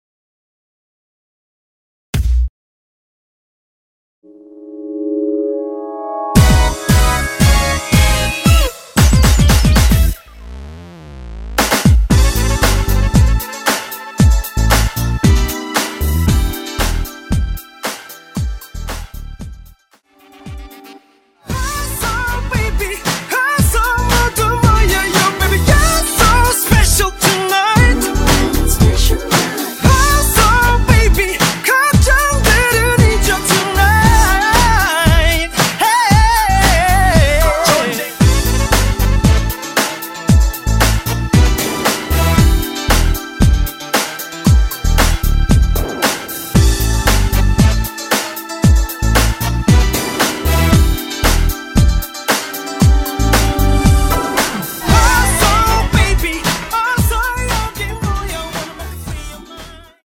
처음 시작 랩 부분은 베이스 드럼 부분부터 시작 하시면 됩니다.
Em
앞부분30초, 뒷부분30초씩 편집해서 올려 드리고 있습니다.
중간에 음이 끈어지고 다시 나오는 이유는